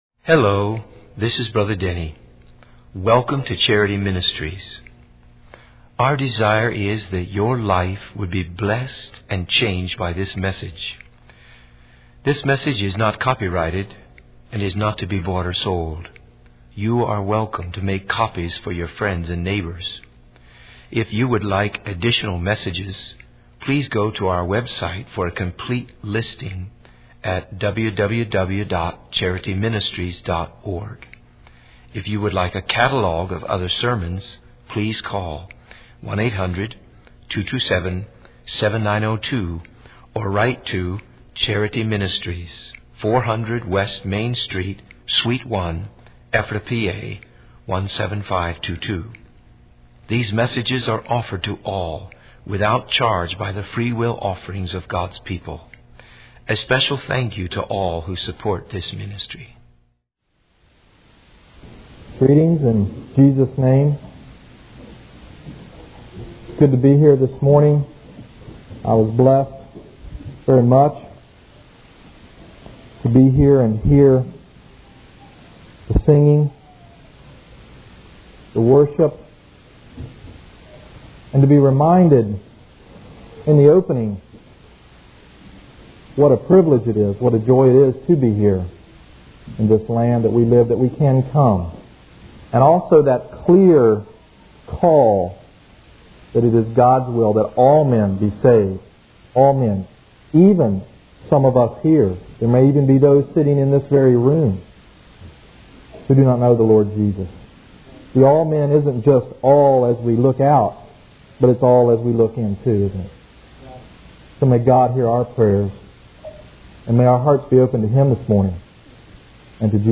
In this sermon, the preacher discusses the concept of being a slave to fashion and challenges the audience to consider what they are truly enslaved to. He emphasizes the importance of being a bondservant to Christ and living a lifestyle that aligns with God's truth. The preacher highlights that fashion is often used as a means to identify with certain groups or individuals, and that clothing can reveal a person's beliefs, feelings, and general approach to life.